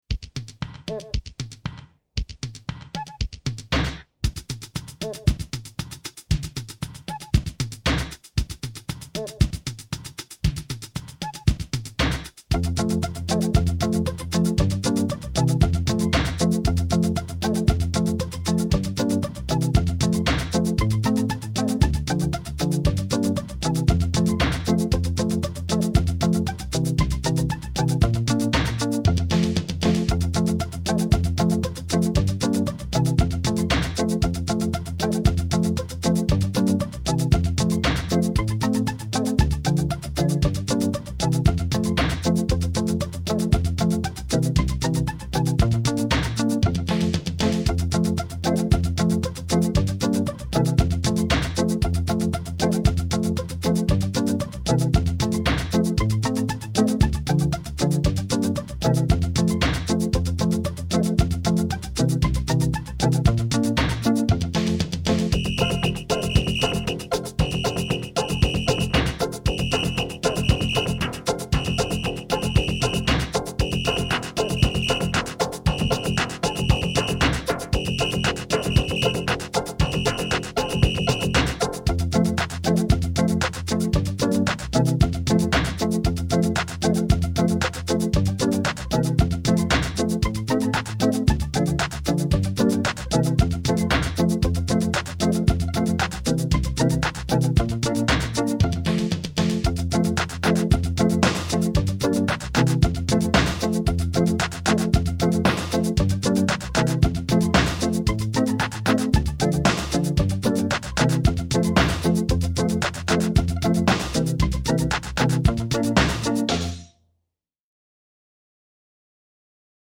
Some Christmas ditties that I created oodles of years ago on a Yamaha V50 (All tunes arranged and performed by me)